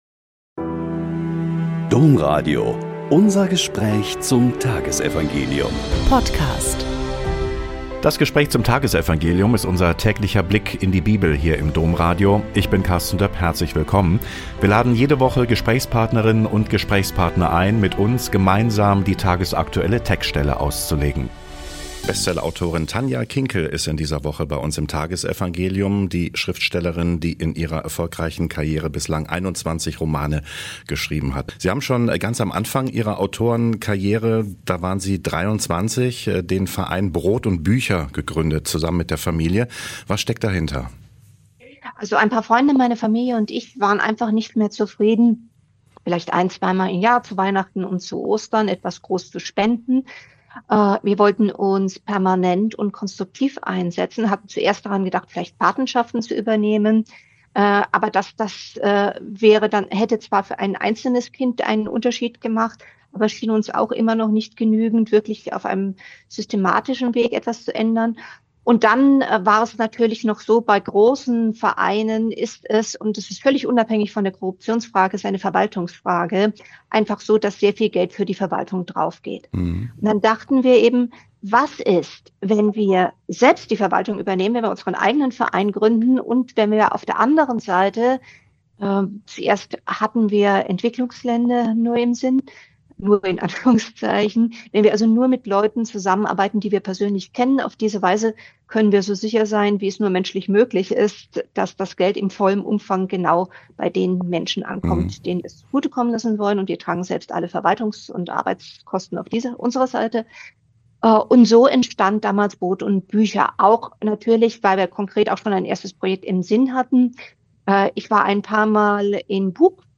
Joh 1,29-34 - Gespräch mit Tanja Kinkel ~ Blick in die Bibel Podcast